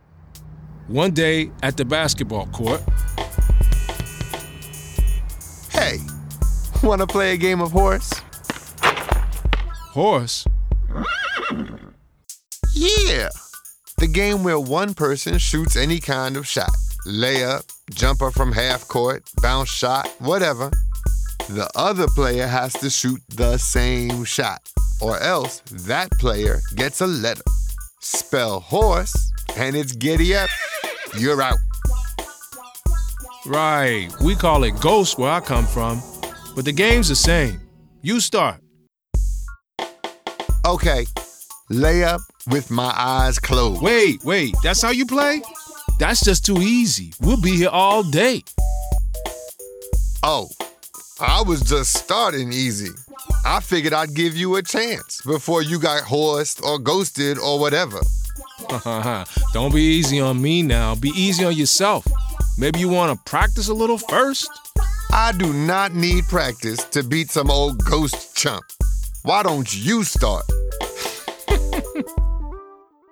ALA Odyssey Award for Excellence in Audiobook Production